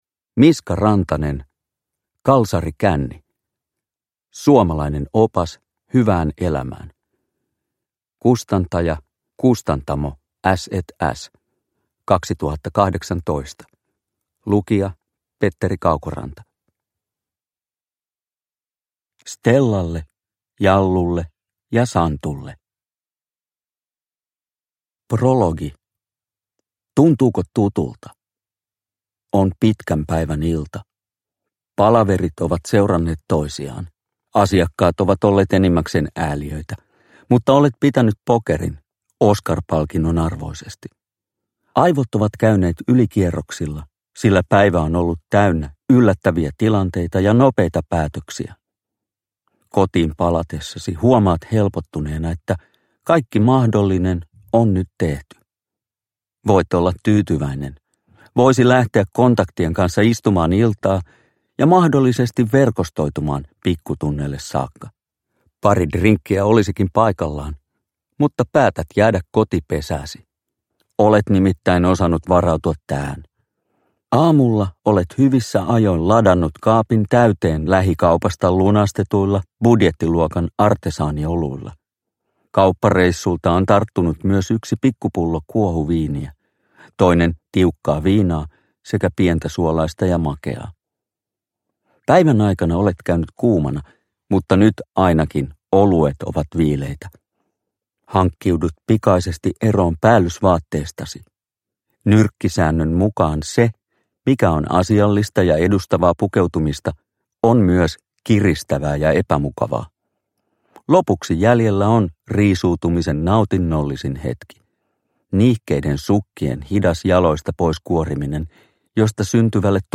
Kalsarikänni – Ljudbok – Laddas ner